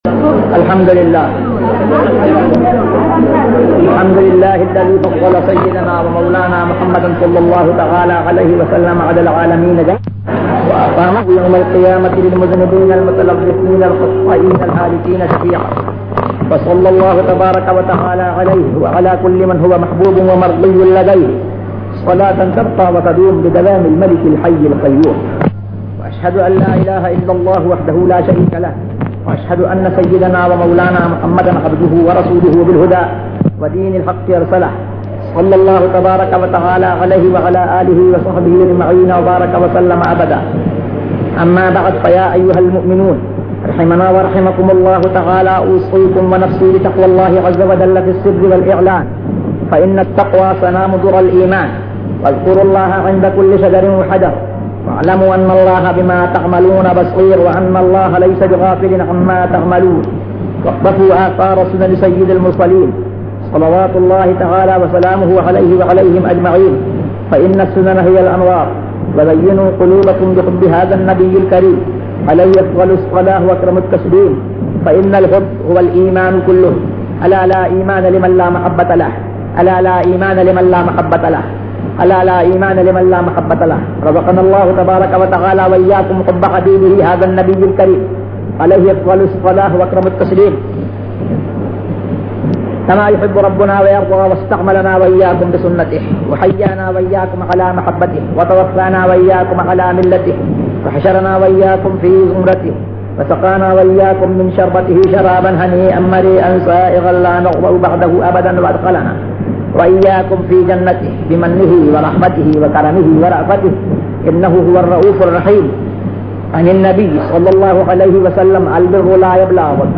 Khutbah Zeenatul Masajid
تقاریر